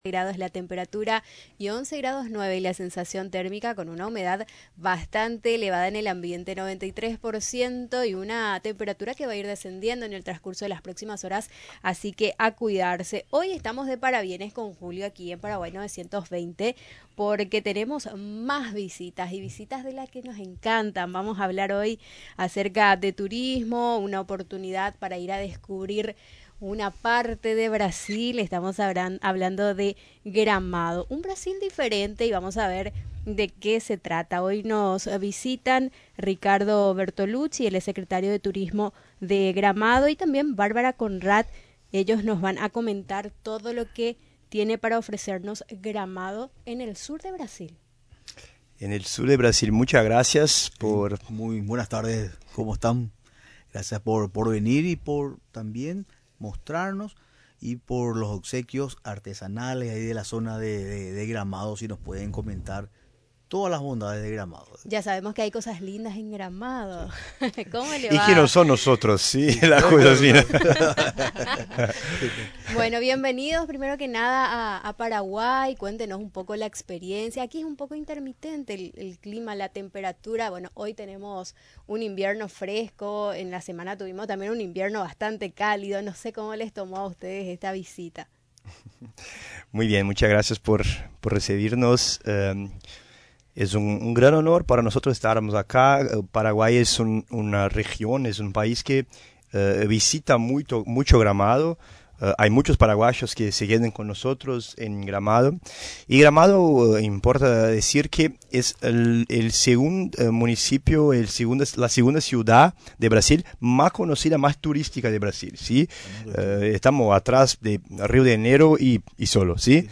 quienes llegaron hasta los estudios de Radio Nacional del Paraguay.